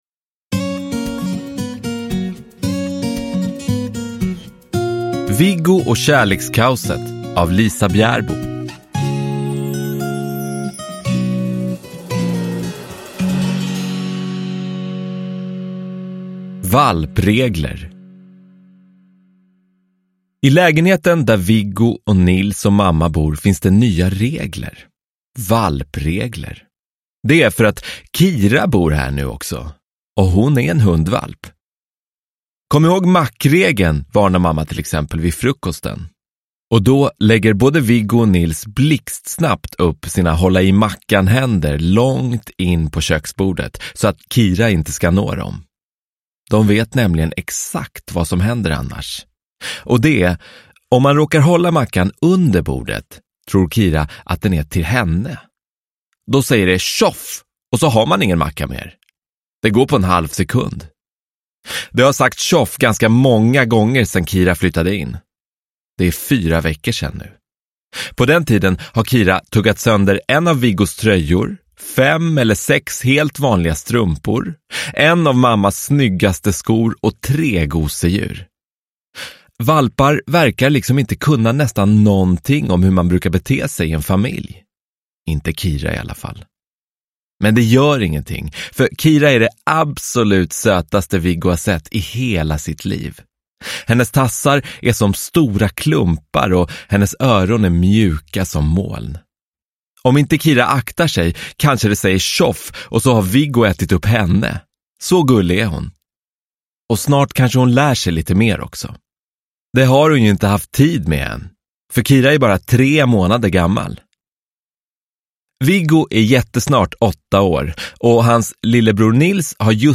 Viggo och kärlekskaoset – Ljudbok – Laddas ner